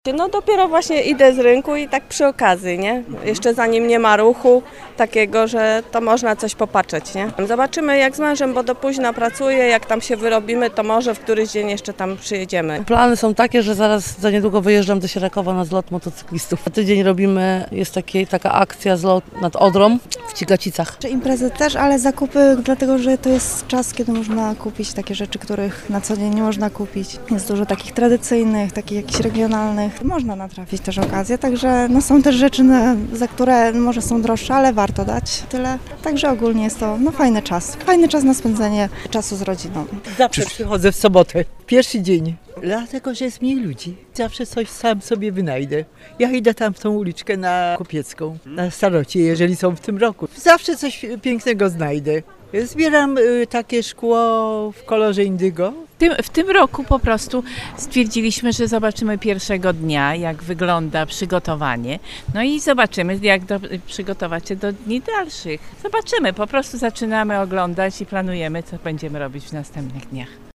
Na zielonogórskim deptaku pojawiło się mniej straganów niż w latach poprzednich, chociaż jak podkreślali odwiedzający jest w czym wybierać.
Z zielonogórzanami udało nam się porozmawiać o ich wrażeniach i planach związanych z winobraniem: